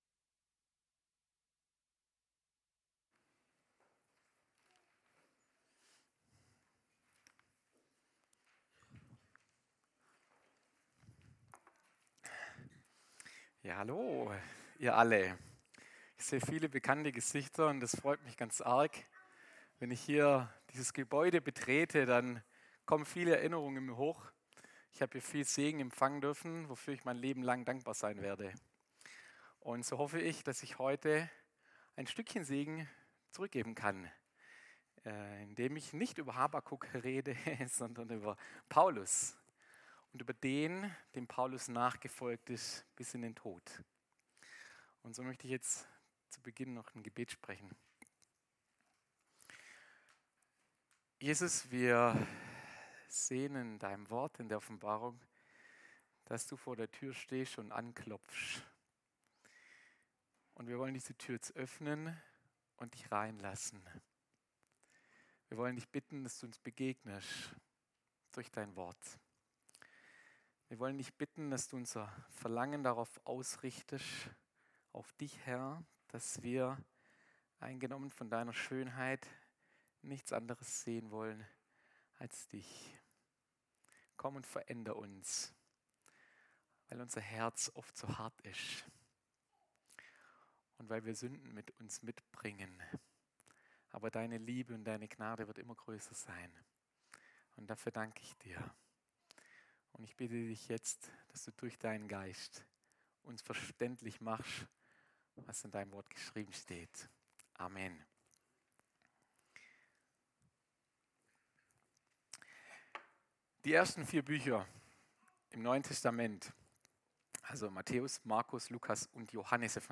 Gottesdienst am 03.08.2025